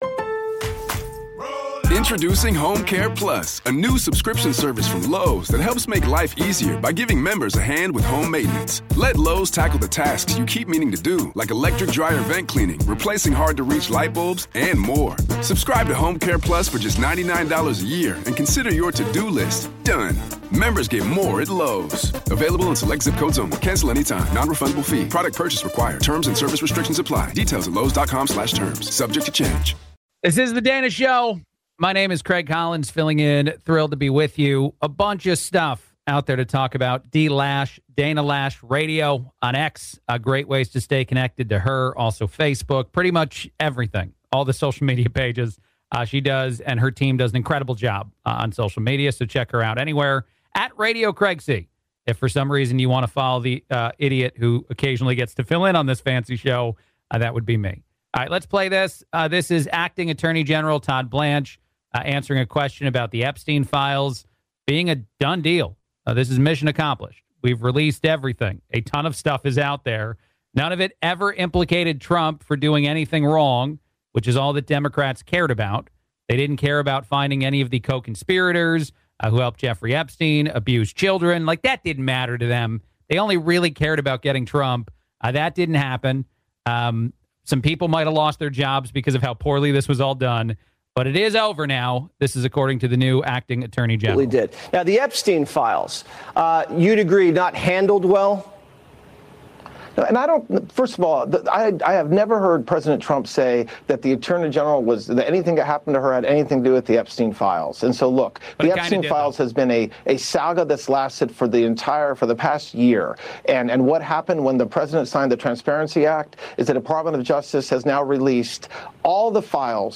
In his first interview since being named Acting Attorney General, Todd Blanche shuts down any idea of a release of more info about the Epstein Files. CNN is stunned by the great job numbers.